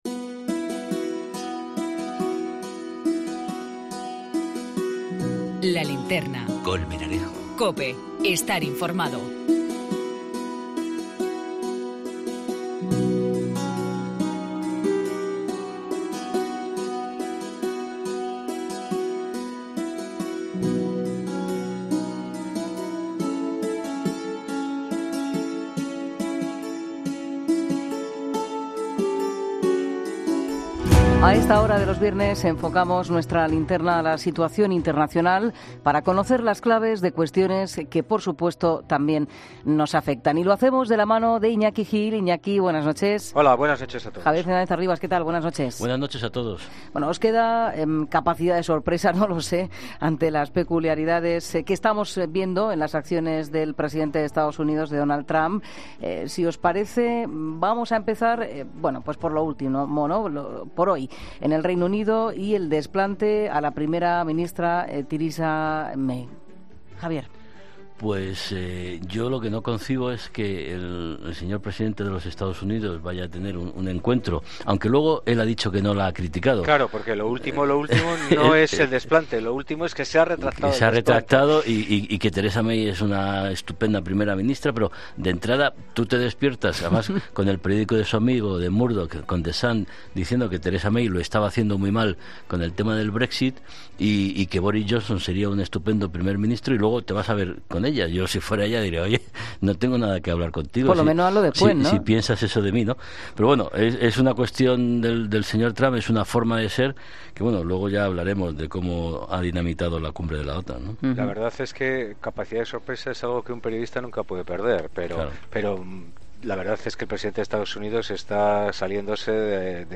Tertulia Internacional en 'La Linterna', viernes 13 julio 2018